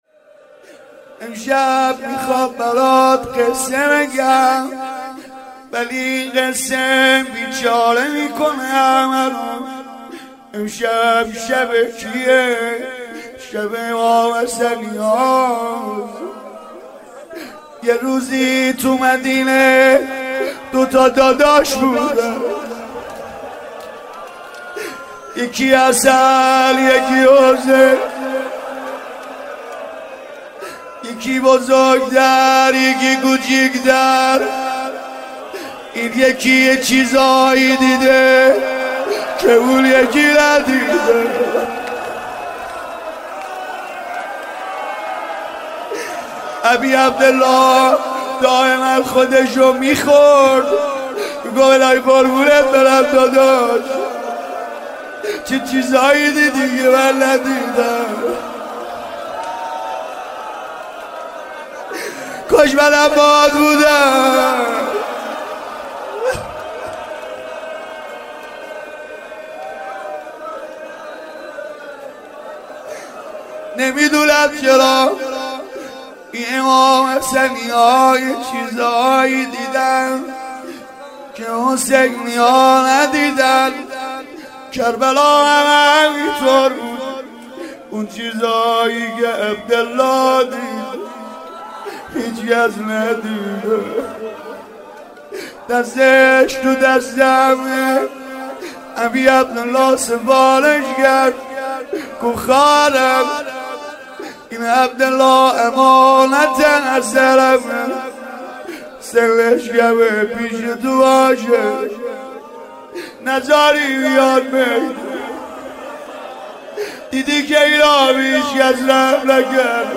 روضه حضرت عبدالله بن حسن